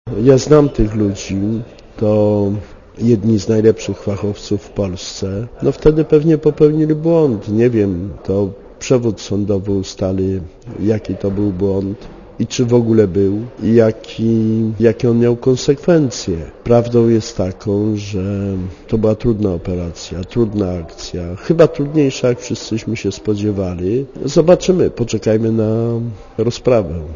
Mówi Krzysztof Janik